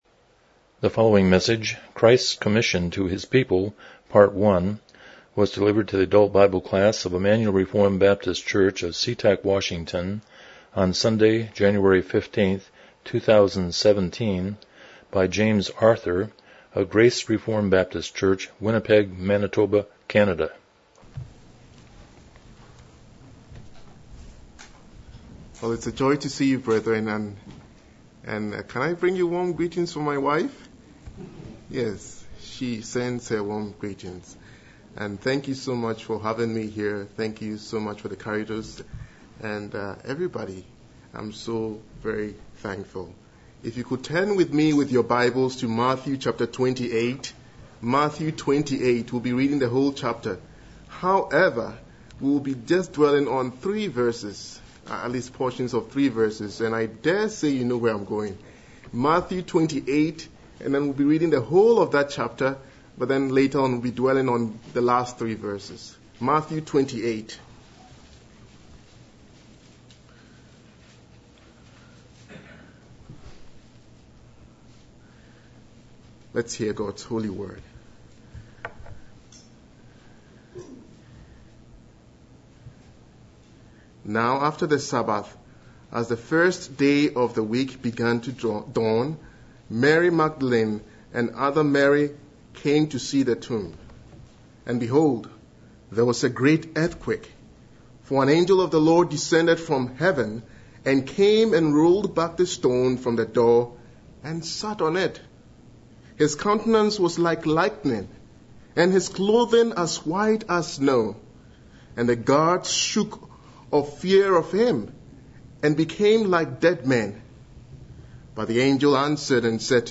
Miscellaneous Service Type: Sunday School « A Comfortable Hope